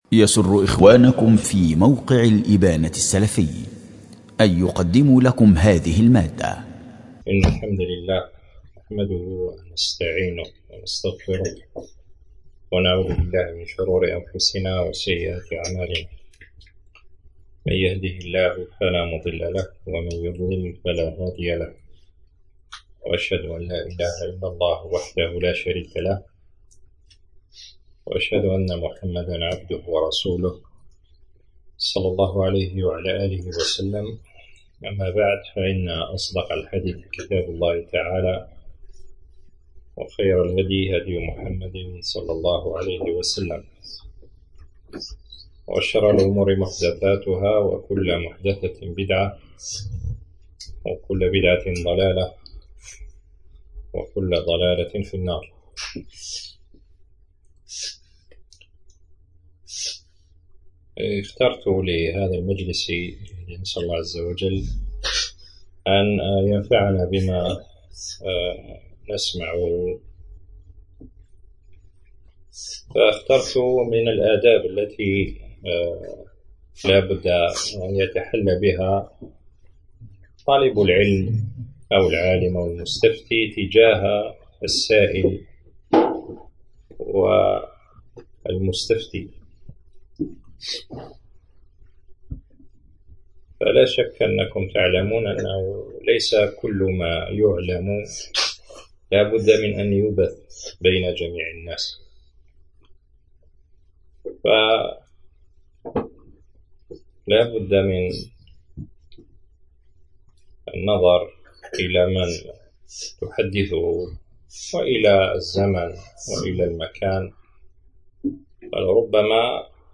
🔹بمدينة خميس الخشنة ولاية بومرداس
دروس ومحاضرات